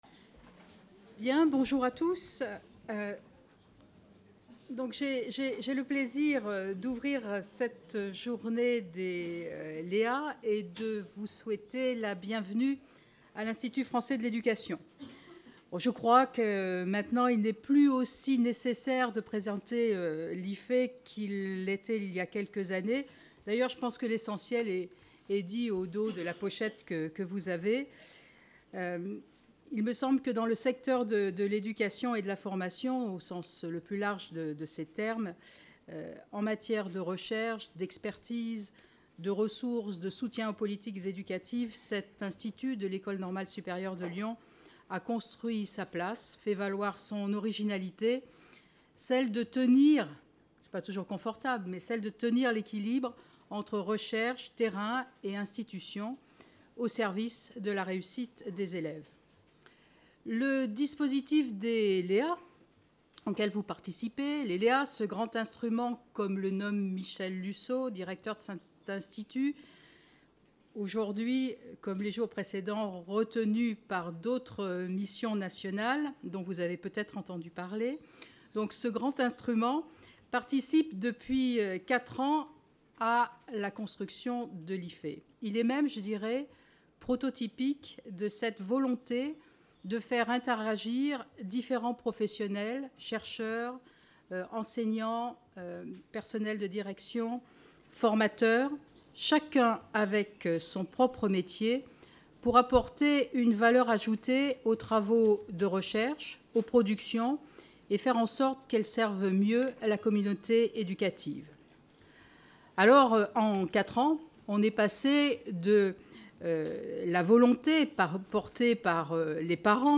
Comment articuler recherche et production de ressources ? La 5° rencontre nationale des LéA qui s'est tenue à l'IFÉ le 13 mai 2015, a interrogé l'articulation entre recherche et production de ressources pour l'enseignement, la formation et l'éducation, à partir des ressources produites depuis 4 ans par les LéA.